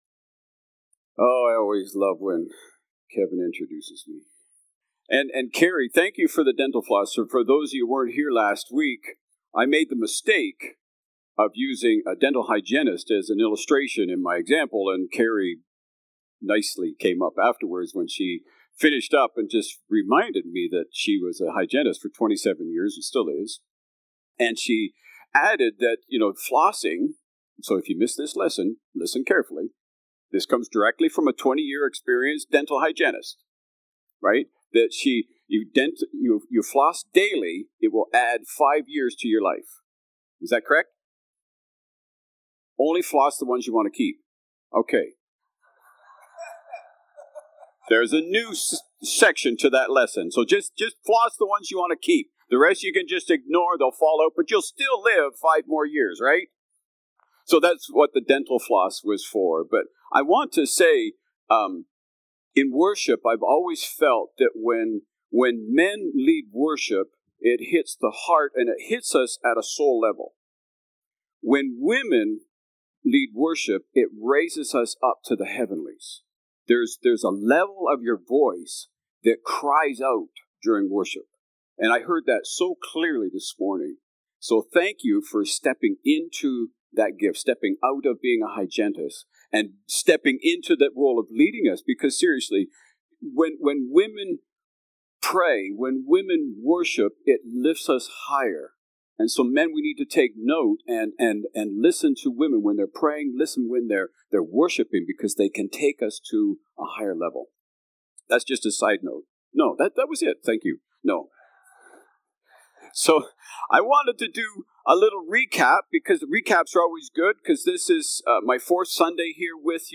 August-10-Sermon.mp3